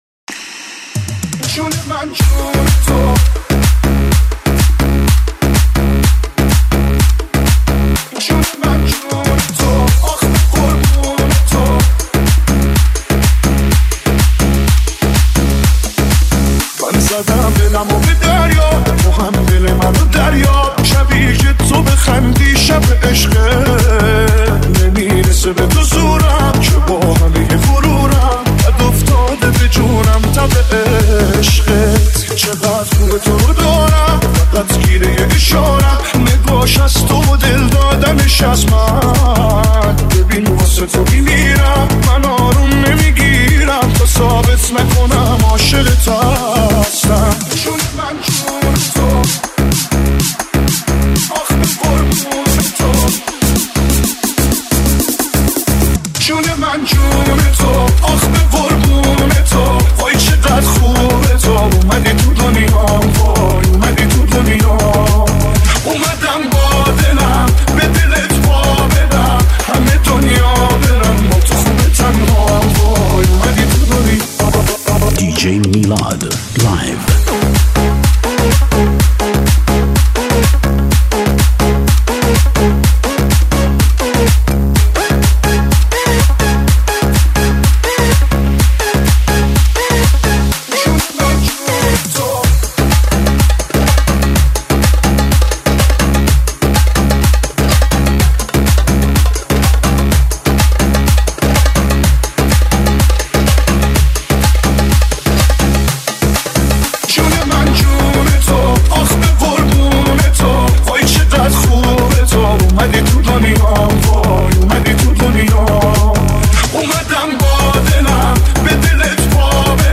• آهنگ جدید ~ ریمیکس